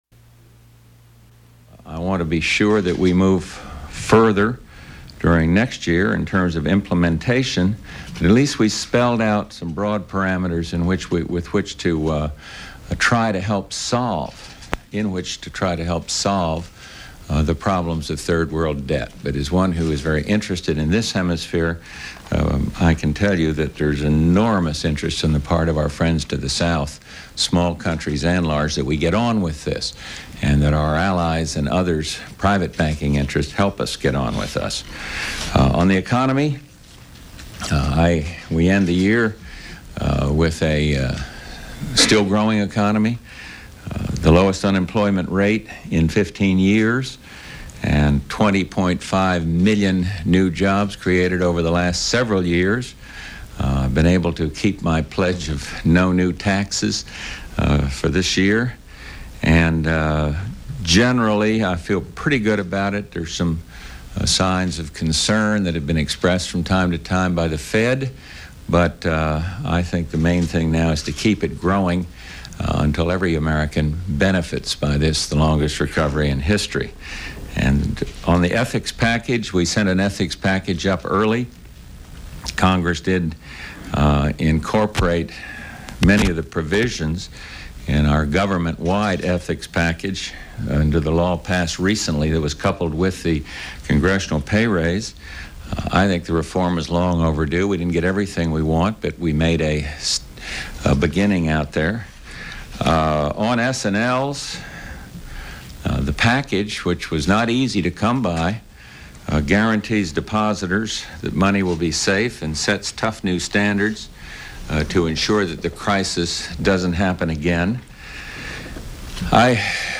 George Bush recaps the high points and achievements of his first year in office and answers reporters questions about topical issues
Questioners are not the White House Press Corps, but a national convention of newspaper editorial writers.
Broadcast on CNN, December 11, 1989.